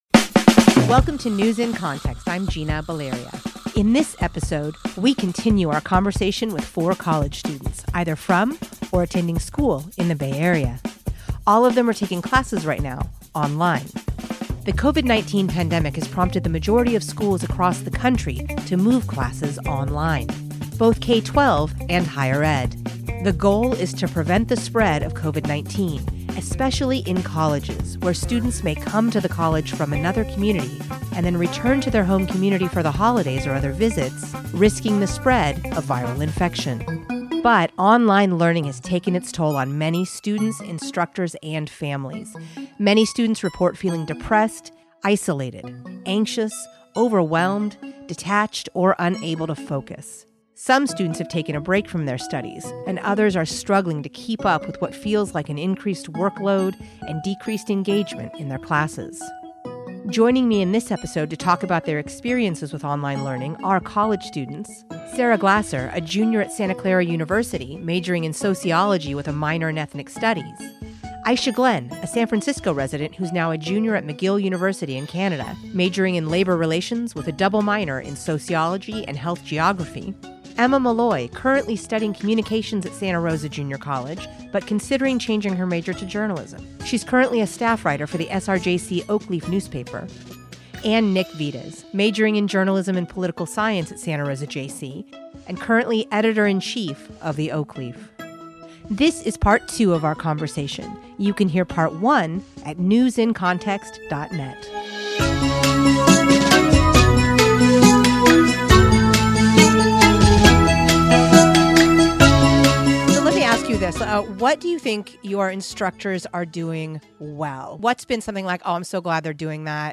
In this episode, we talk with four college students from and/or attending school in the Bay Area - all of them taking classes right now online.